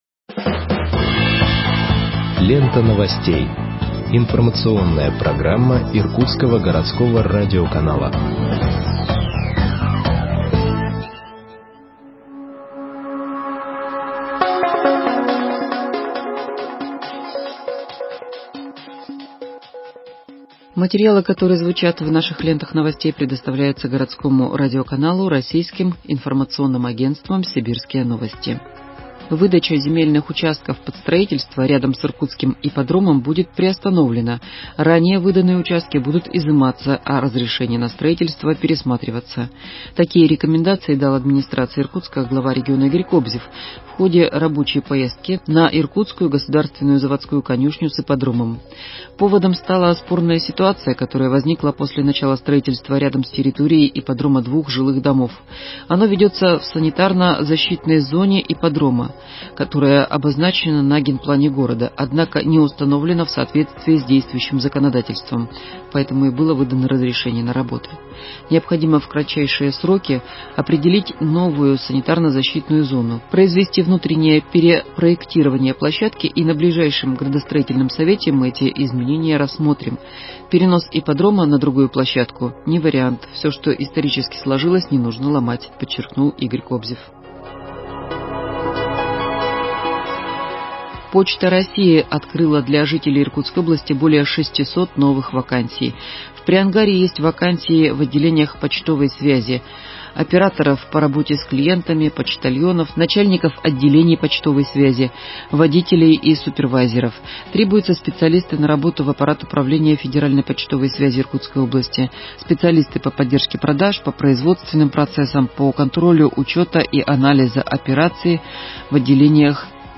Выпуск новостей в подкастах газеты Иркутск от 14.10.20 № 1